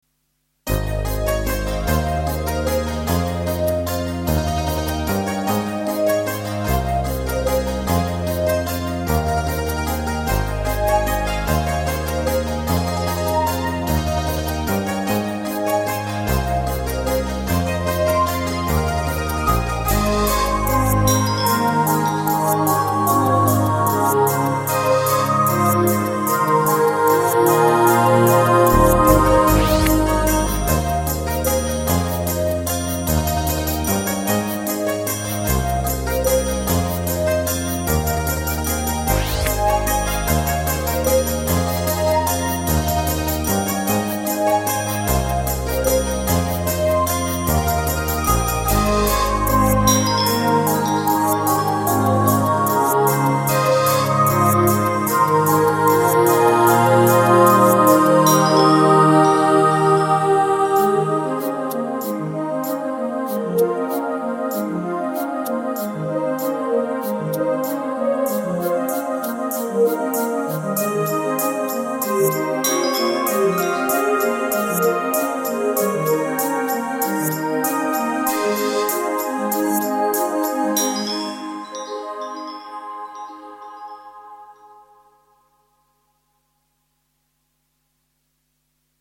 De folkloristische 'dulcimer' speelt hierin de hoofdrol temidden van elektronische koren en instrumenten.
In dit stukje zijn ook wat kerstmelodietjes gebruikt.